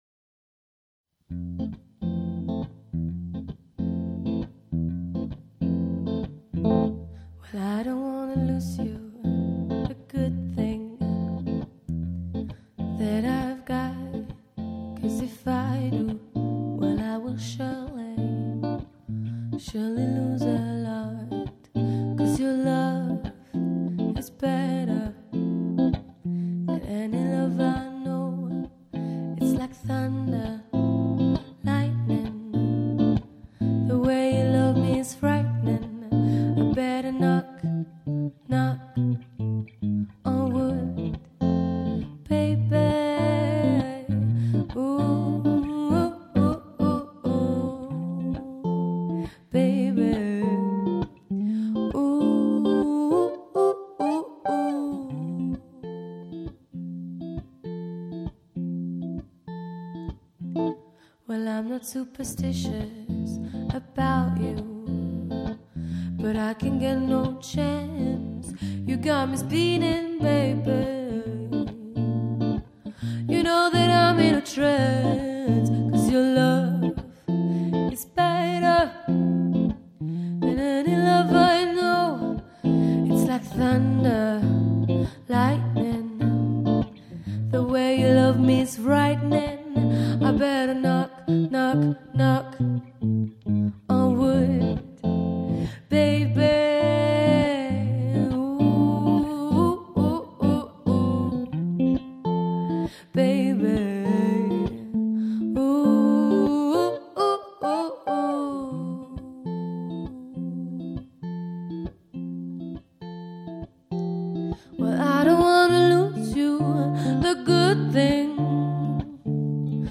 Jazz duo performing modern songs